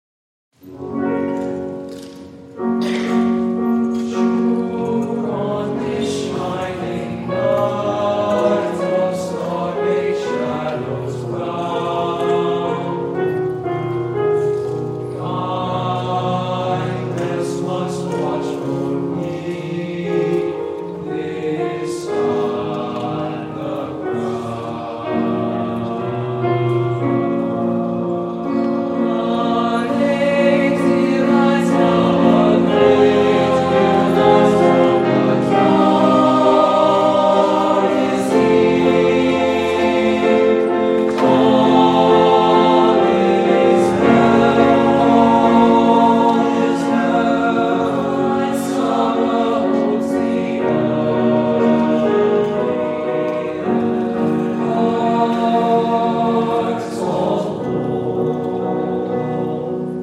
annual winter concert
a mixture of classical and holiday music